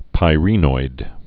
(pī-rēnoid, pīrə-)